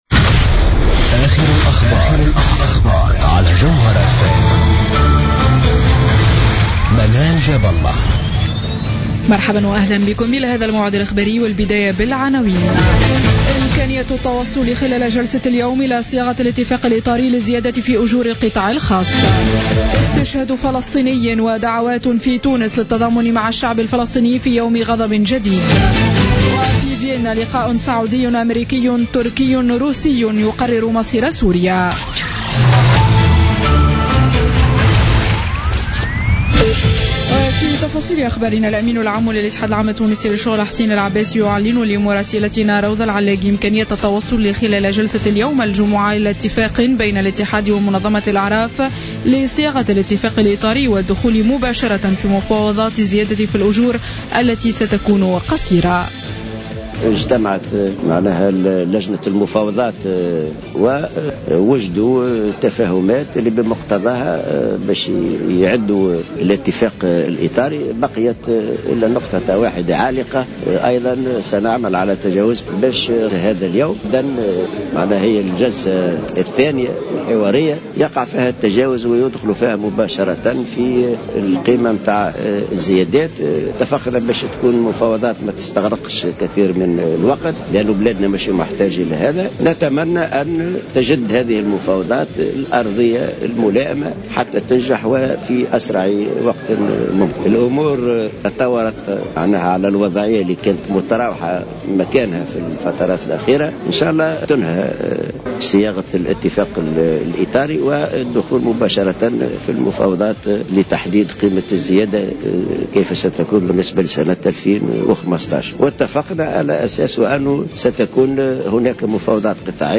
نشرة أخبار منتصف الليل ليوم الجمعة 23 أكتوبر 2015